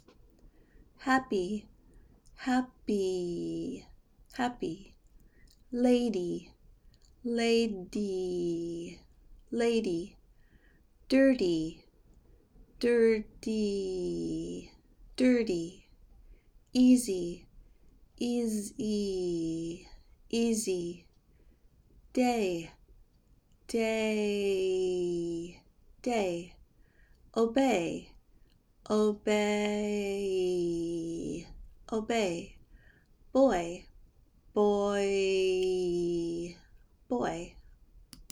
How to Pronounce Y as a Vowel at the End of a Word
For now, just remember that when you see y at the end of a word, it will often have the long e sound.
Practice with Y at the end of a word